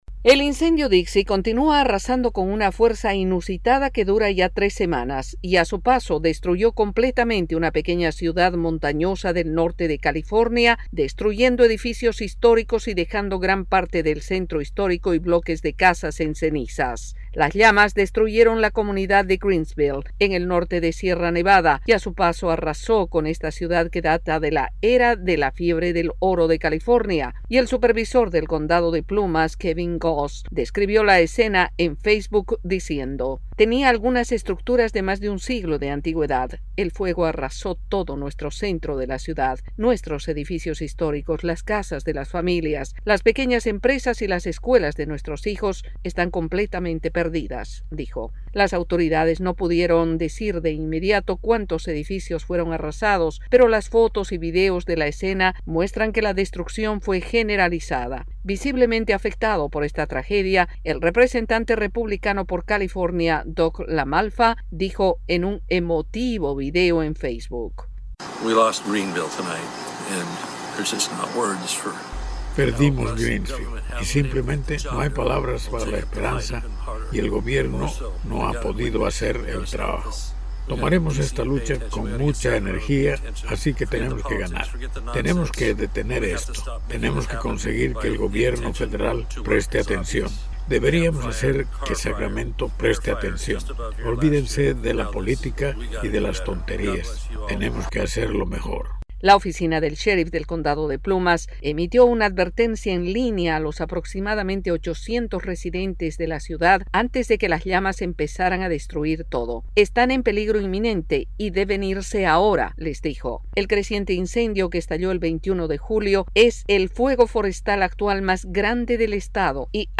California está abrumada por los incendios y las llamas consumen todo a su paso incluida una pequeña ciudad que perdió todo. Informa